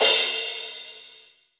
CRASH_P.WAV